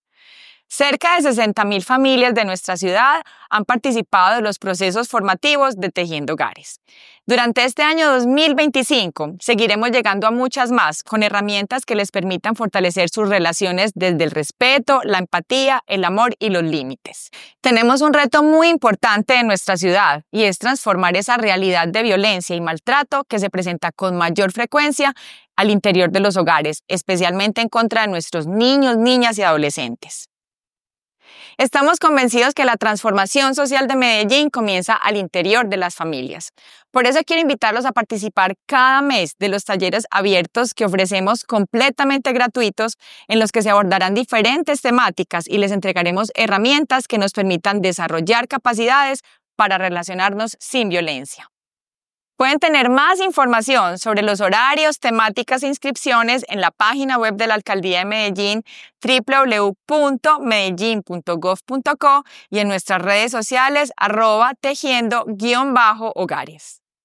Primera_Dama_talleres_salud.mp3